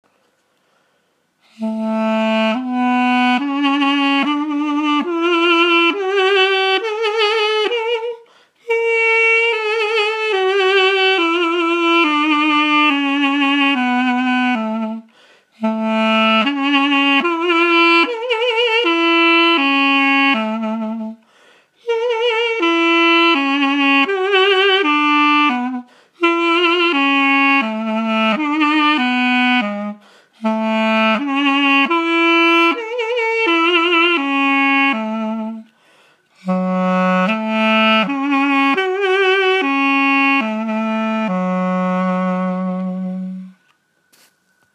Дудук A, MKS
Дудук A, MKS Тональность: A